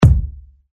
GOLPEFX KICK241
Ambient sound effects
golpeFX_Kick241.mp3